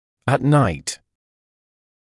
[ət naɪt][эт найт]ночью, в ночное время